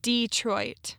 (DĒ-troit)